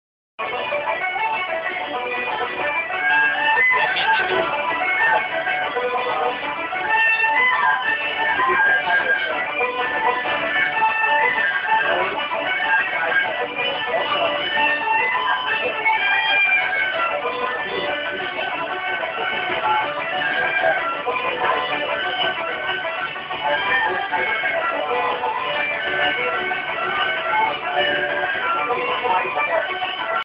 Momentan sitzen wir im Uisge Beatha bei einheimischer Live-Musik.
Eine Premiere: Habe 30 Sekunden vom Konzert aufgenommen und mitgeschickt.